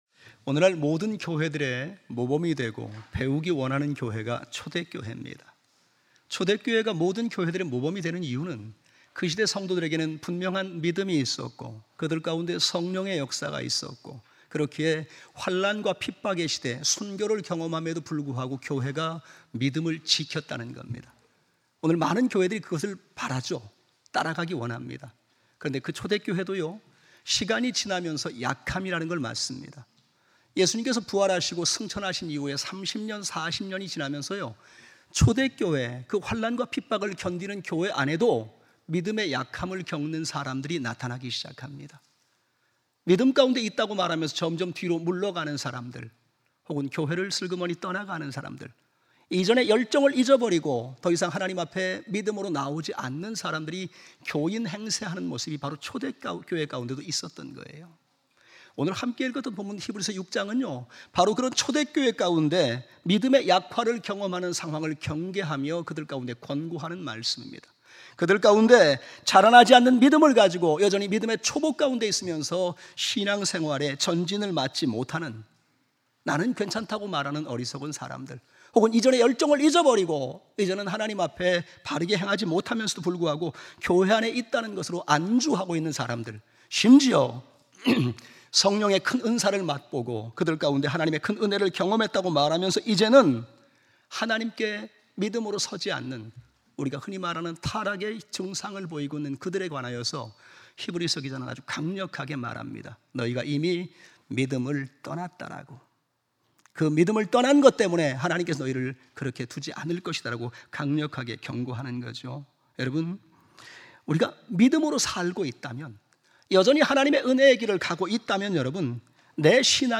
주일오전예배 3 페이지 | 경주제일침례교회